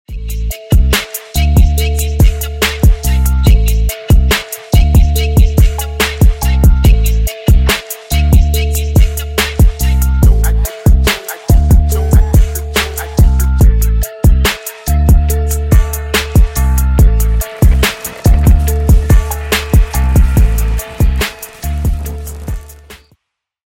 working in studio A & B.